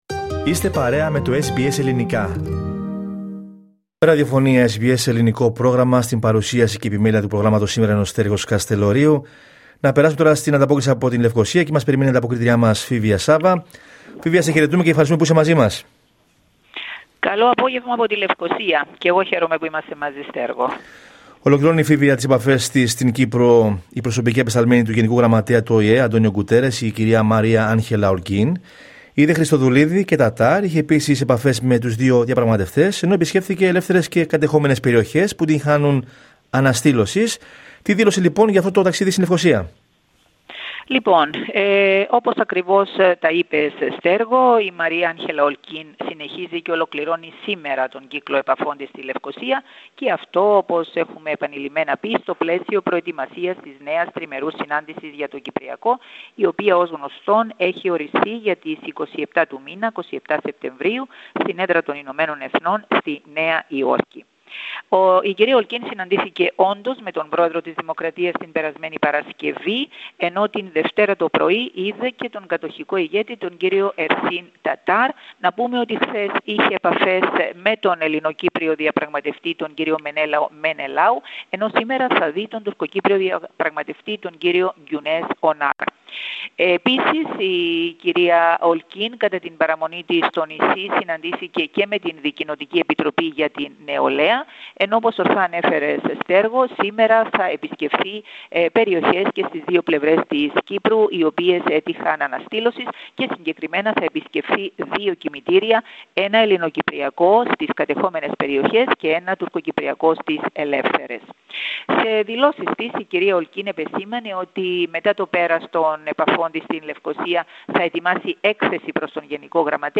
Ακούστε την εβδομαδιαία ανταπόκριση από την Κύπρο